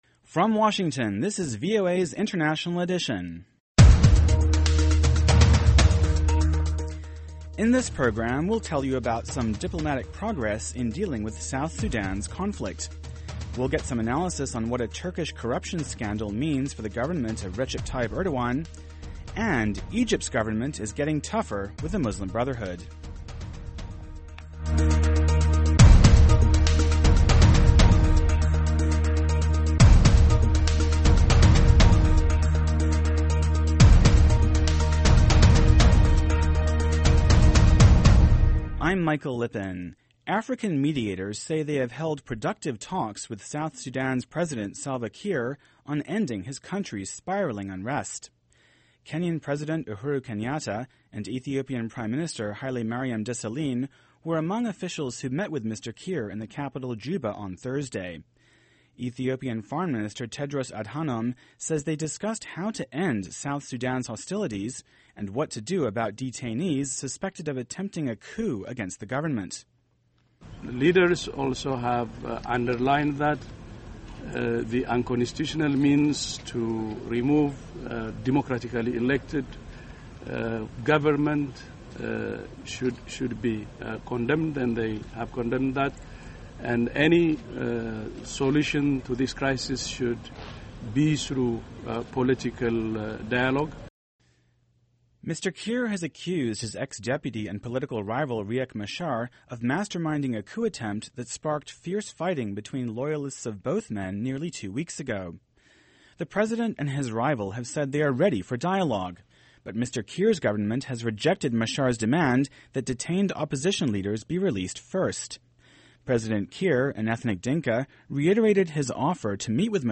Thirty-minutes of in-depth world news, plus highlights of the day's business and sports.